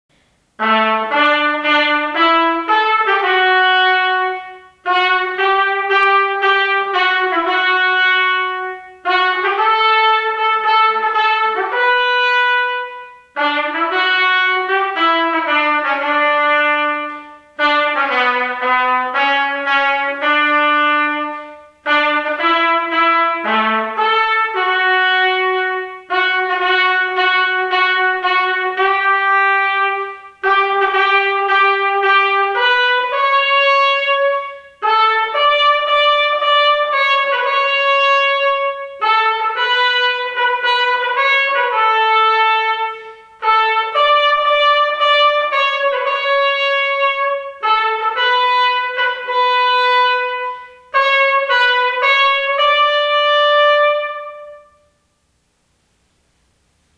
トランペットのソロ演奏でお聴きください。